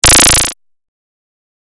Lazer-10
lazer-10.mp3